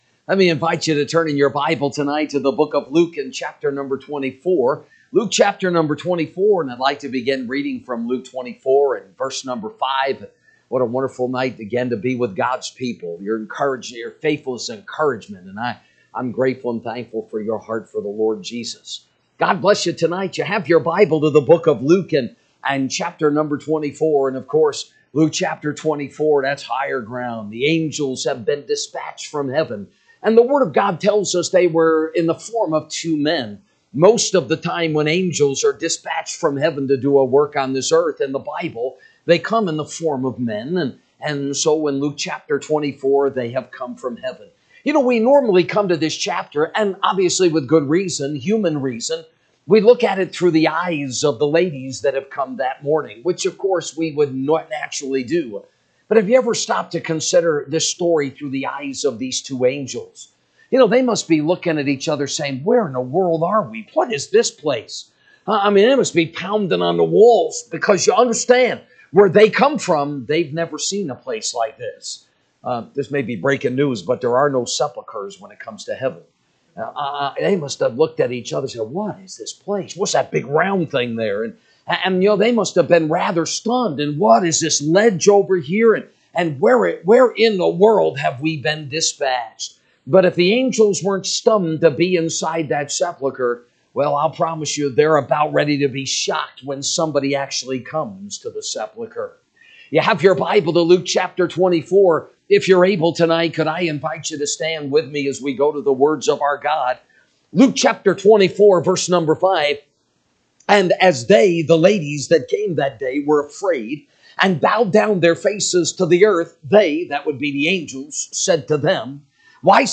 March 18, 2025 Tue. Revival Service